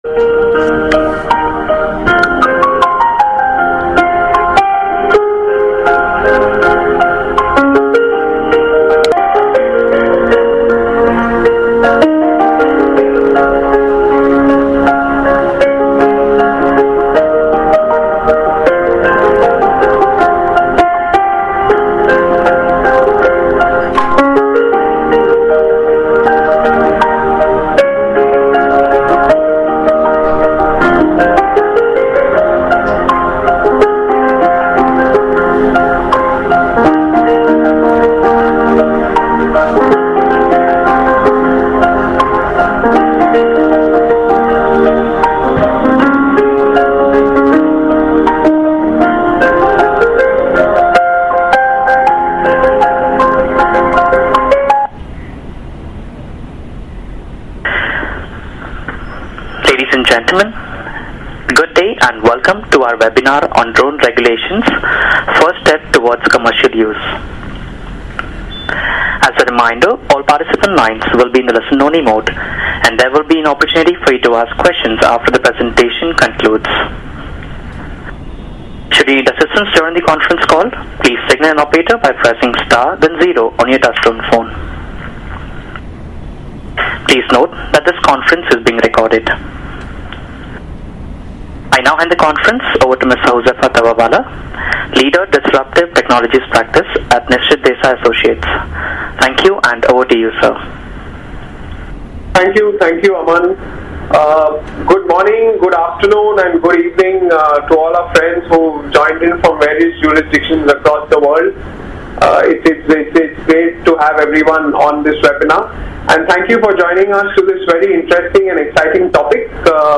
Webinar: Drone Regulations: First Step Towards Commercial Use